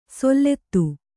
♪ sollettu